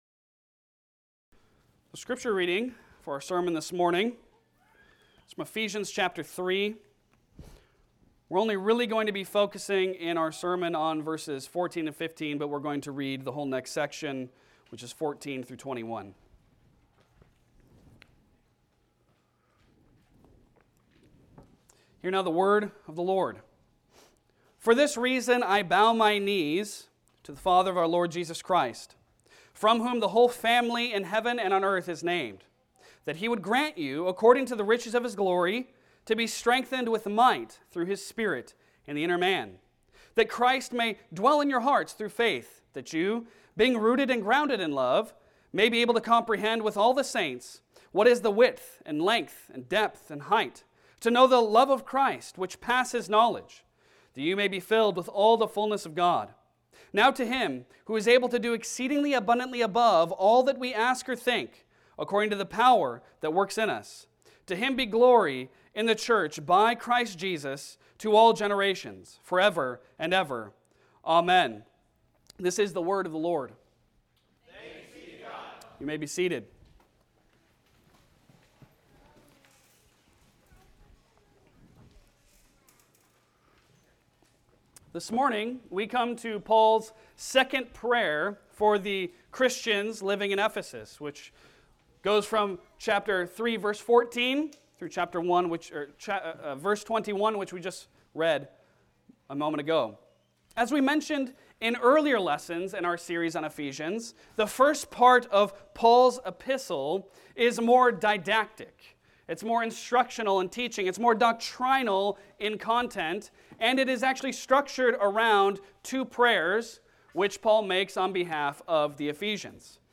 Passage: Ephesians 3:14-15 Service Type: Sunday Sermon